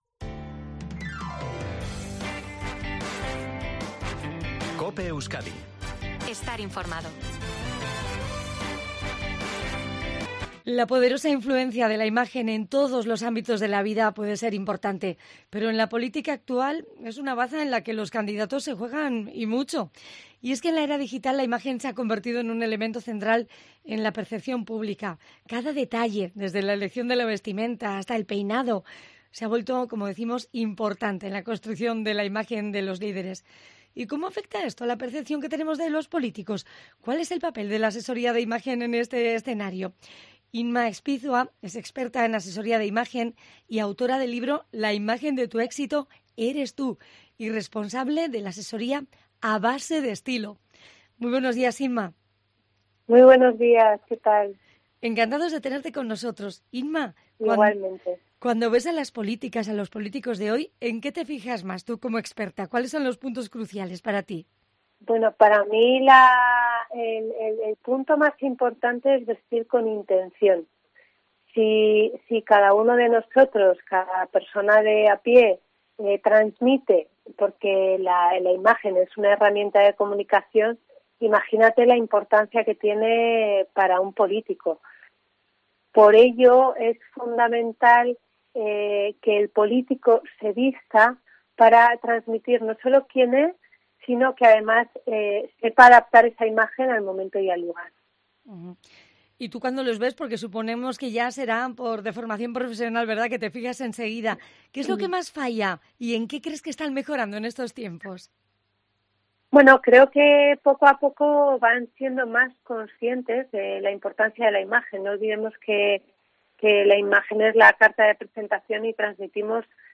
Escucha las claves de esta experta que ha pasado por COPE Euskadi para analizar la importancia de cómo vemos a los candidatos
Escucha en la entrevista su enfoque integral que busca lograr una imagen coherente con la personalidad y objetivos de cada individuo.